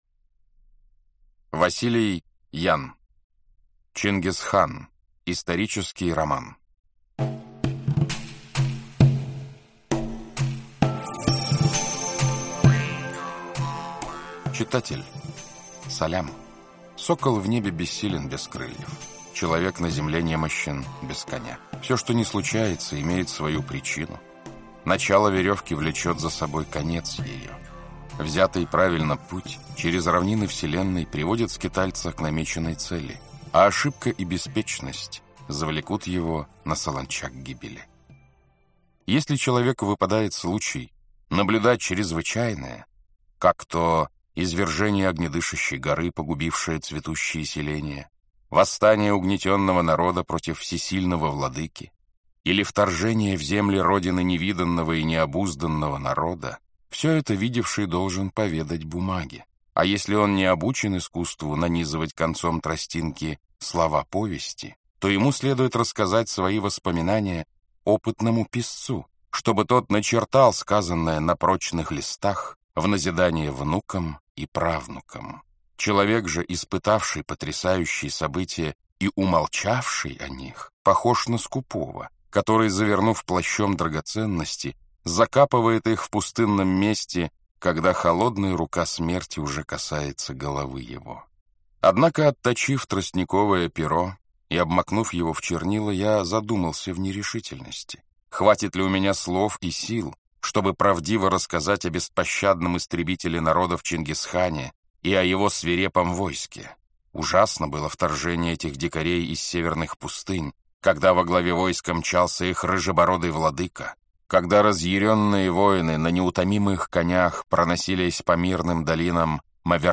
Aудиокнига Чингисхан Автор Василий Ян Читает аудиокнигу Александр Клюквин. Прослушать и бесплатно скачать фрагмент аудиокниги